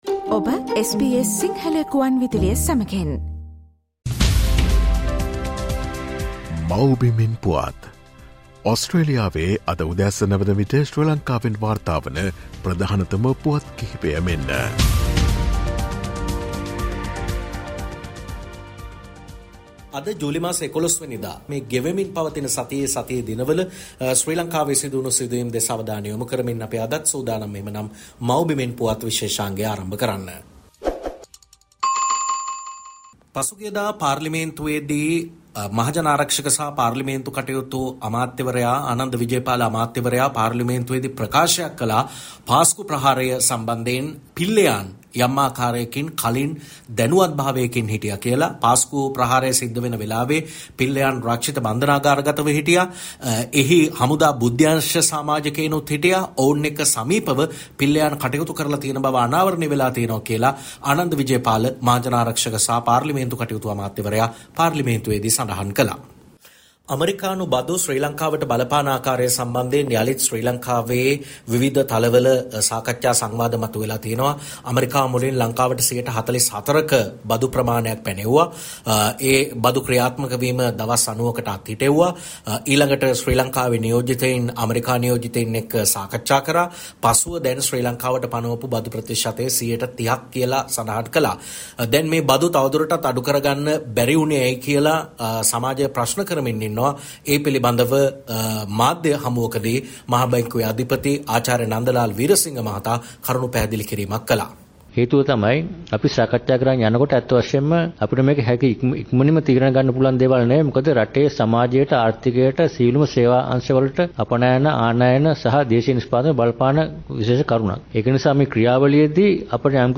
ශ්‍රී ලංකාවේ සිට වාර්තා කරයි